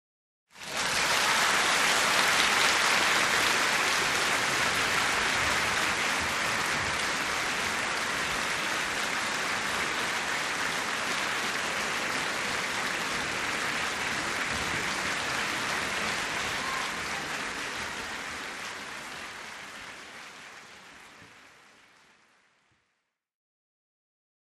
Concert Hall Theater
Applause 5 - Large Crowd - Shorter Interior Music Hall Orchestra